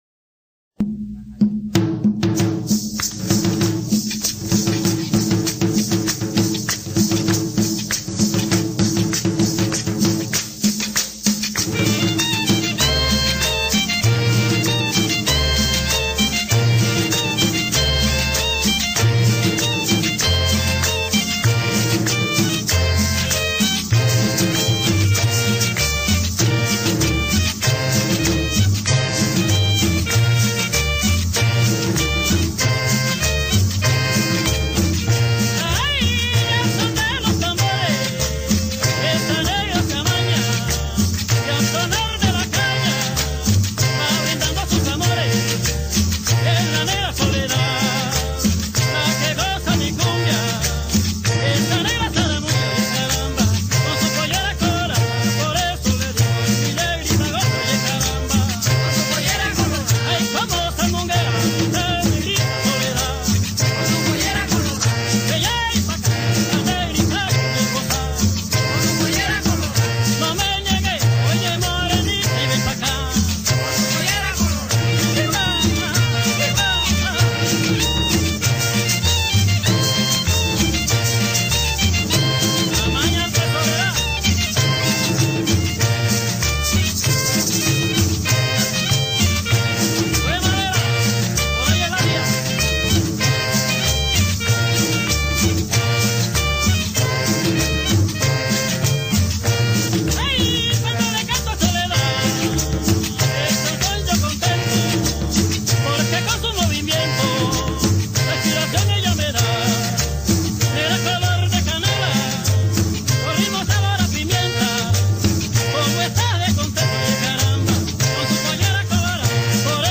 un colega se lo había recomendado  como clarinetista.
cumbia